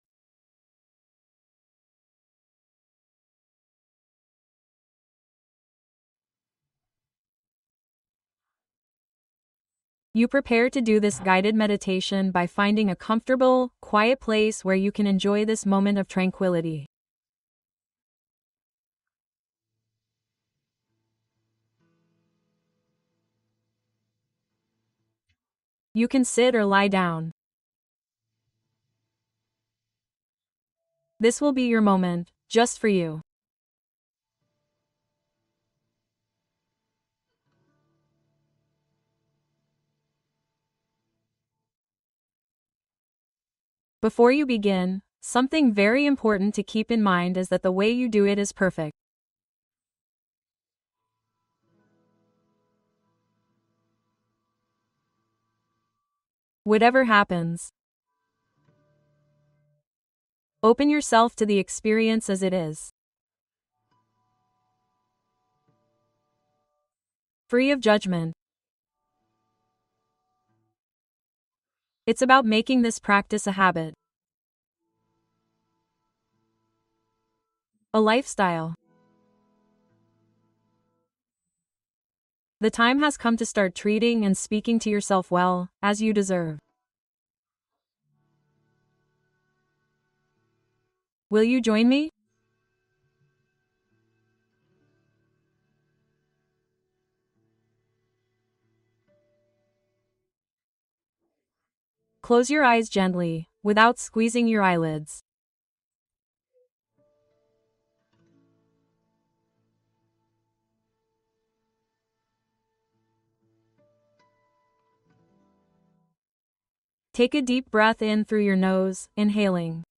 Libera pensamientos negativos con esta meditación guiada poderosa